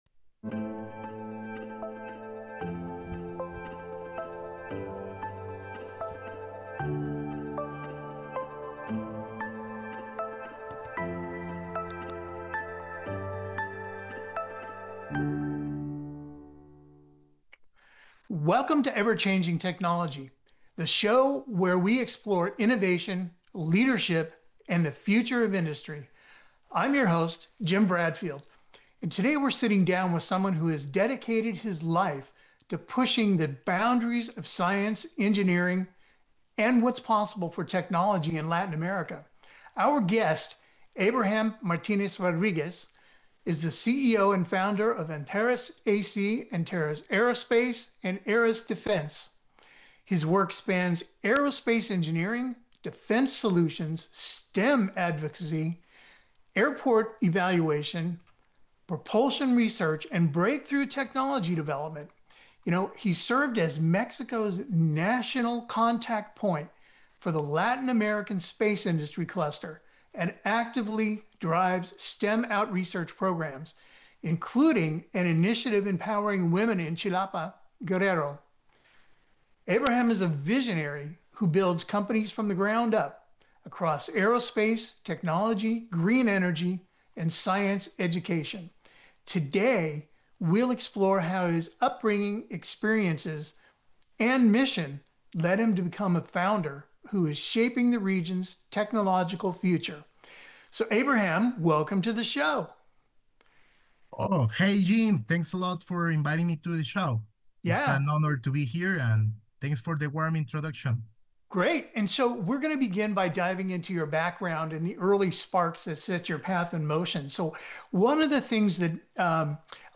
The conversation explores how curiosity, discipline, and purpose can drive technological innovation even in environments where resources and role models are limited.